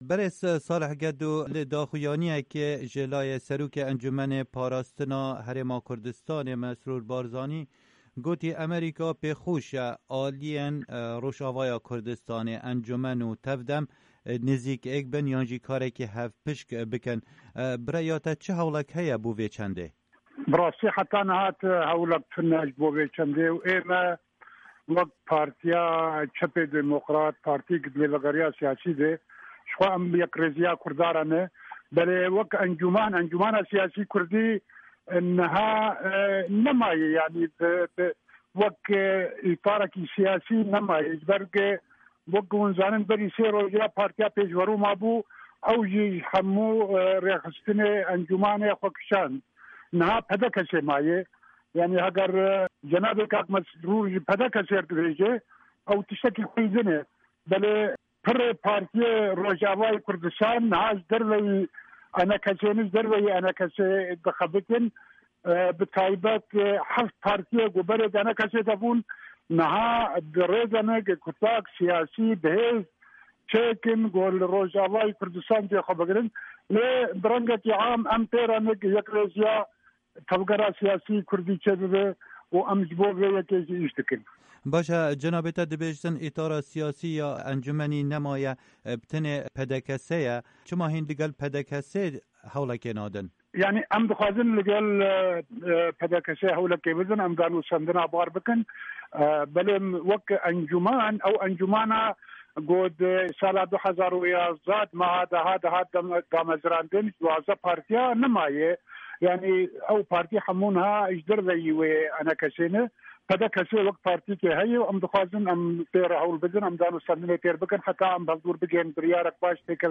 Hevpeyvîn bi Salih Gedo re